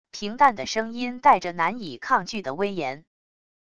平淡的声音带着难以抗拒的威严wav音频